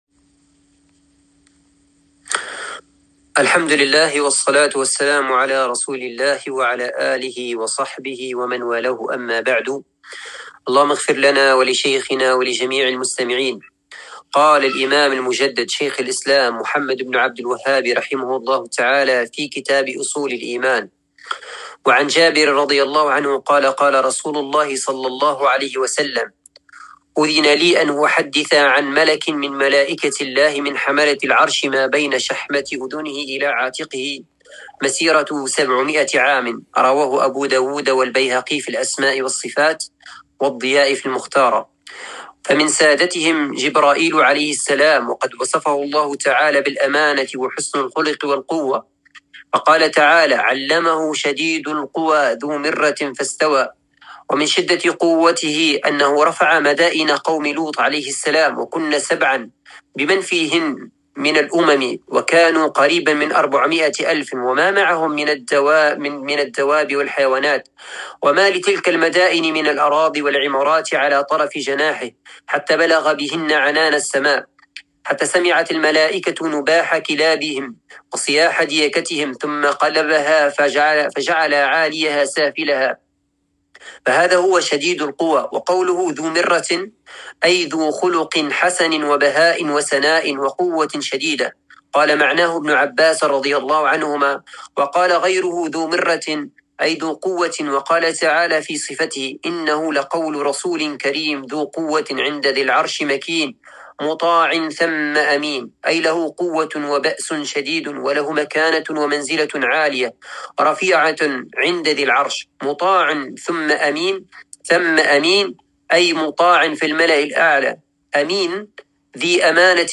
الدرس السادس من كتاب أصول الإيمان